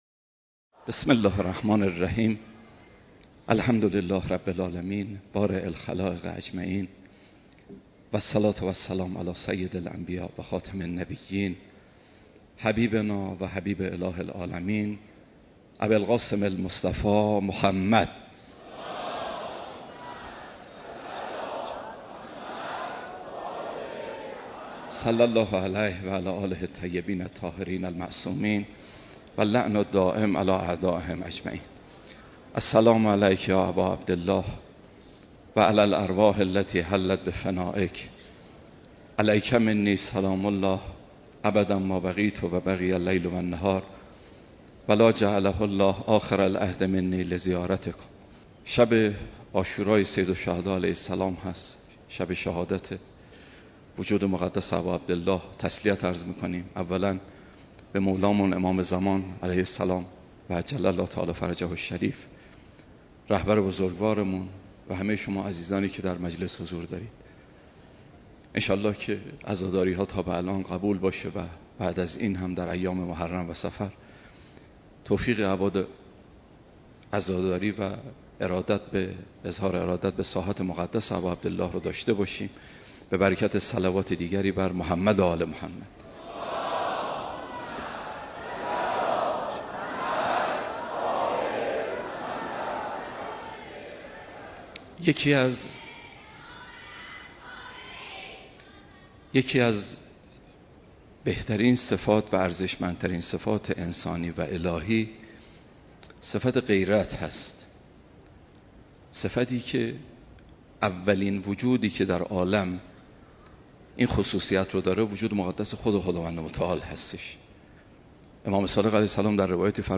مراسم سوگواری حضرت اباعبدالله الحسین(ع) در شب عاشورا برگزار شد